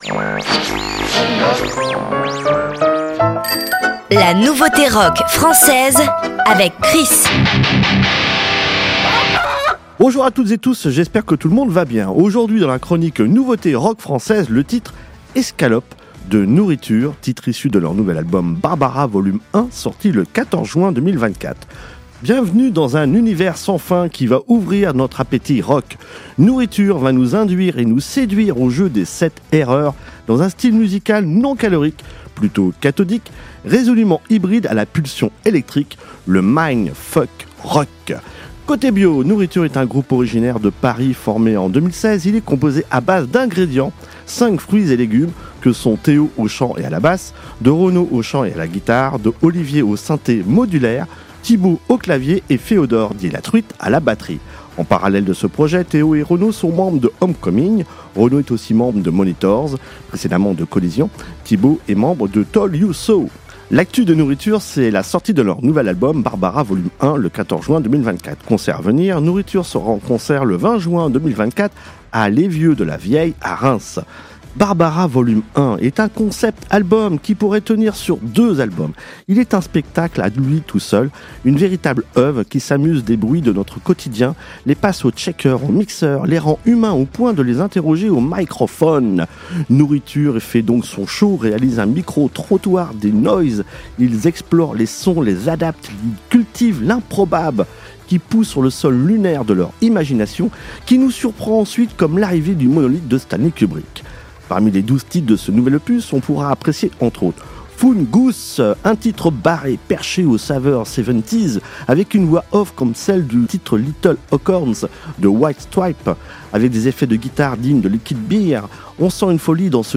La Nouveauté Rock Française